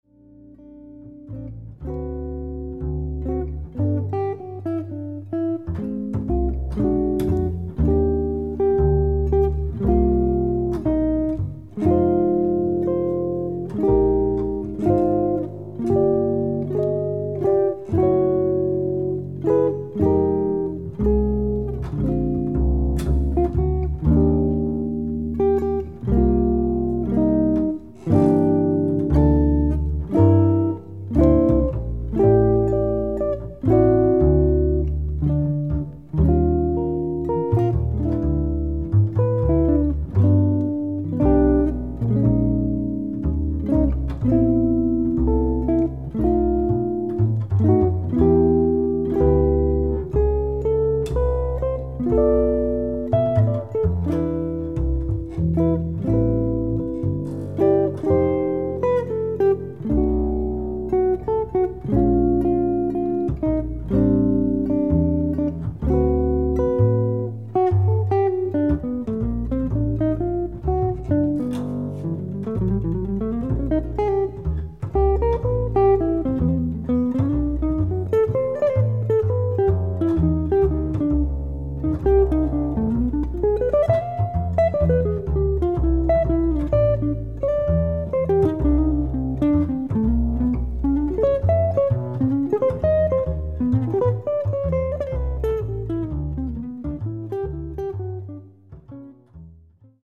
Acoustic Mainstream Jazz auf 10 Saiten
Kontrabass
• dezent & unaufdringlich
• immer swingend, melodisch & harmonisch